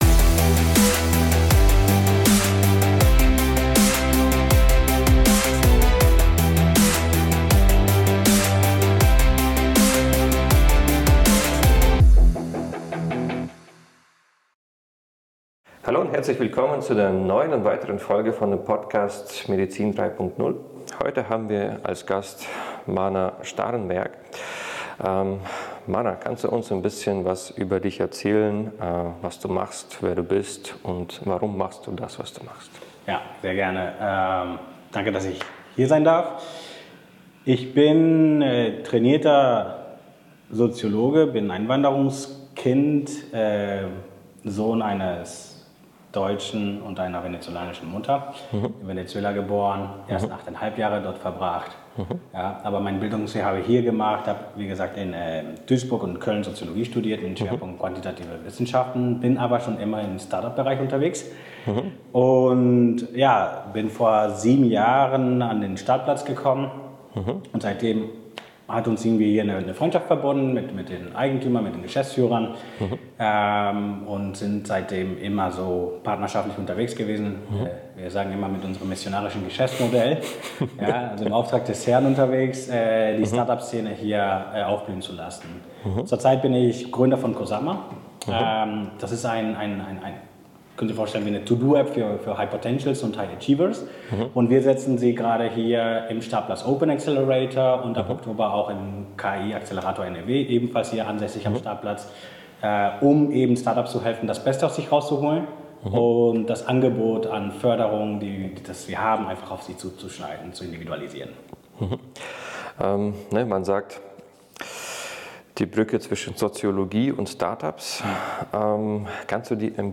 In diesem Vortrag wird die Thematik der Work-Life-Balance umfassend behandelt.